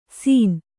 ♪ sīn